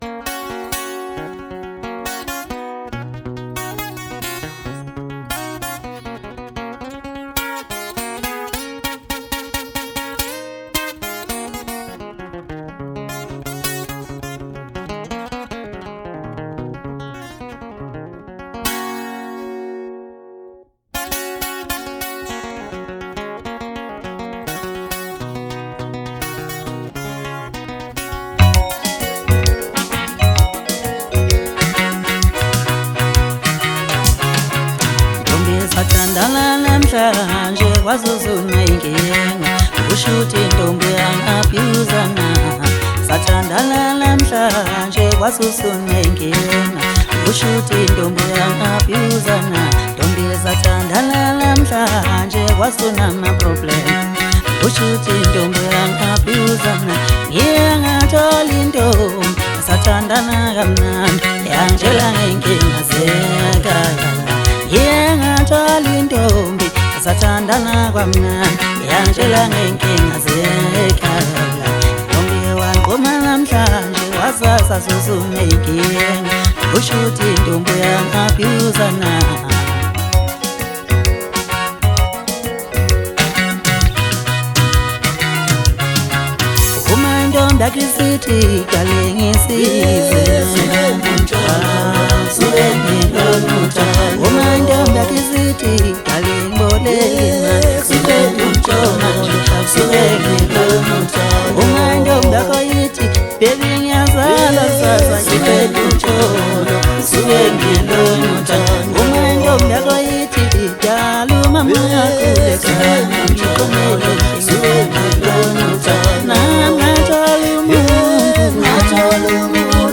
Home » Maskandi » Maskandi Music
Maskandi Songs